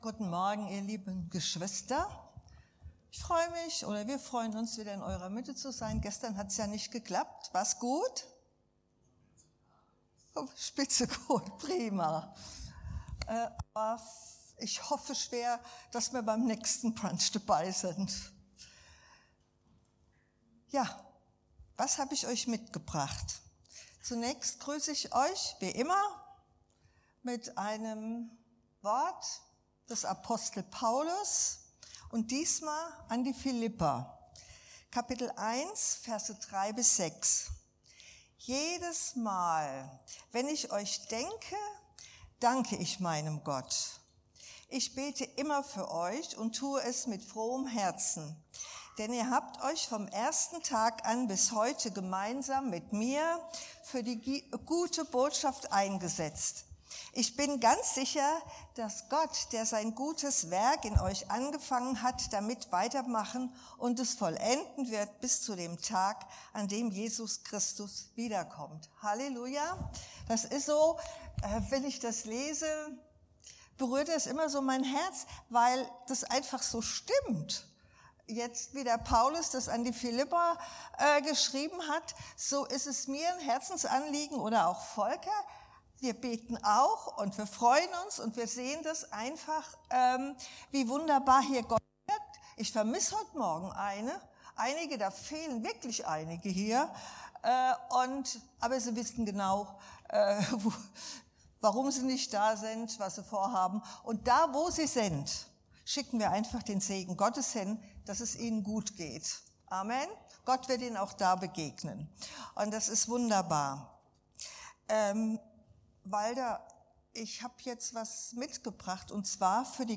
Dienstart: Predigt Die sieben Worte Jesu am Kreuz